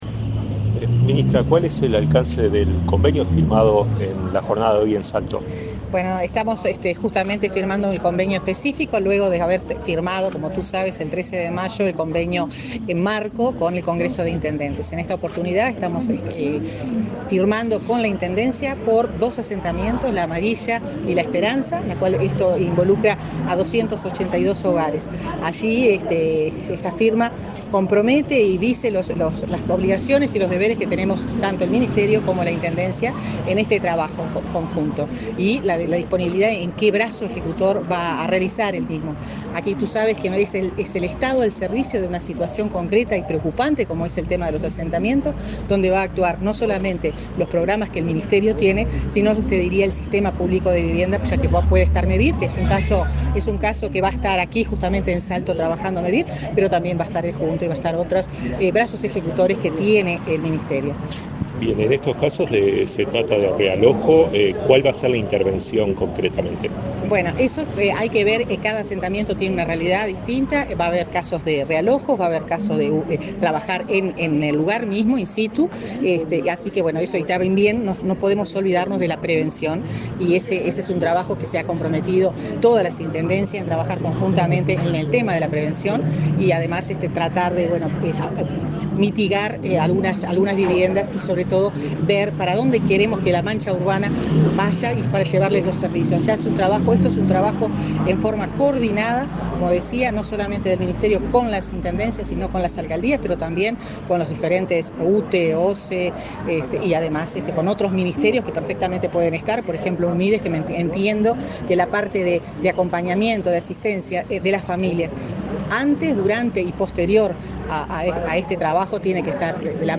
Entrevista a la ministra de Vivienda y Ordenamiento Territorial, Irene Moreira
Entrevista a la ministra de Vivienda y Ordenamiento Territorial, Irene Moreira 07/07/2022 Compartir Facebook X Copiar enlace WhatsApp LinkedIn Este jueves 7 de julio, la ministra Irene Moreira firmó convenios con las intendencias de Paysandú y Salto, y el lunes 11 lo hará con la comuna de Artigas, para la concreción del plan Avanzar. Tras el evento, la jerarca efectúo declaraciones a Comunicación Presidencial.